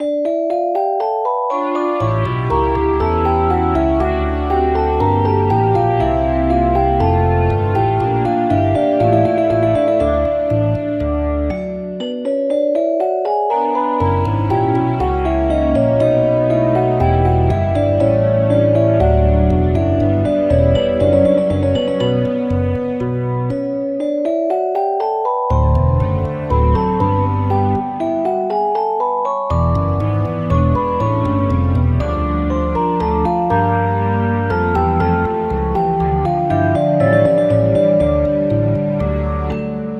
暗い楽曲
【イメージ】バロック、パイプオルガン、荘厳 など